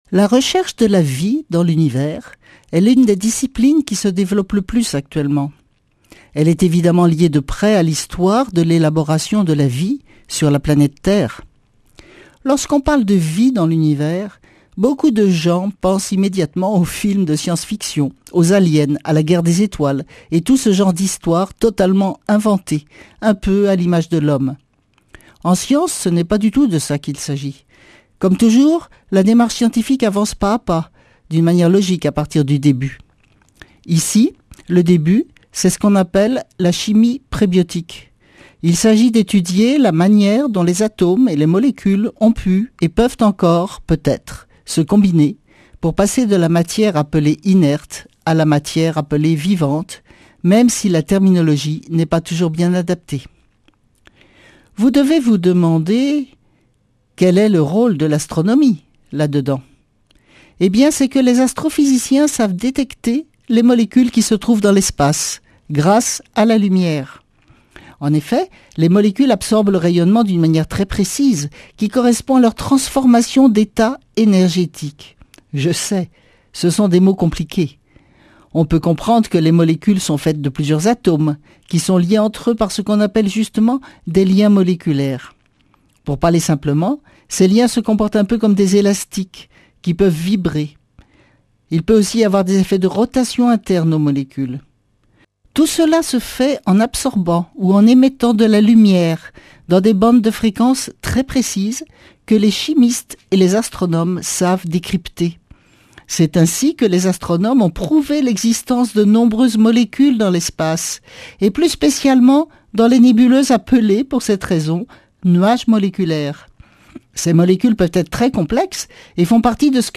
Speech
Une émission présentée par
Astrophysicienne